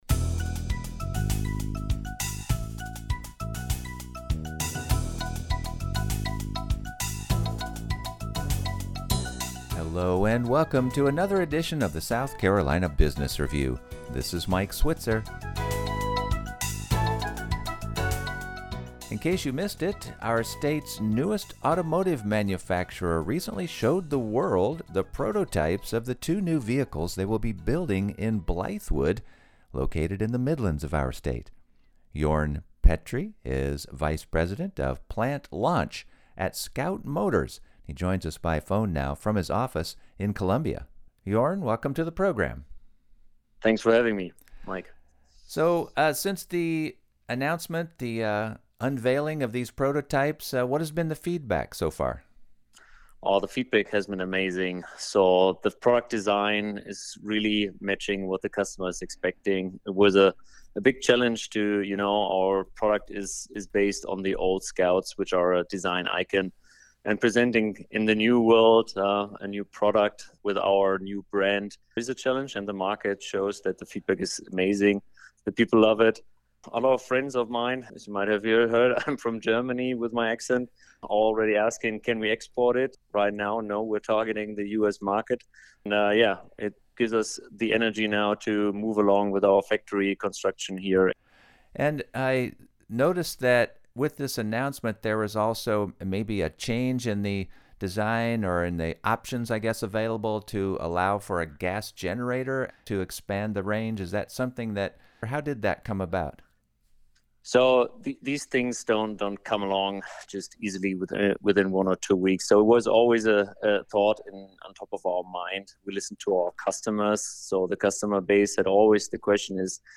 South Carolina's nonprofits, including its colleges and universities are also regularly featured on the program, as well as many of the state's small business support organizations.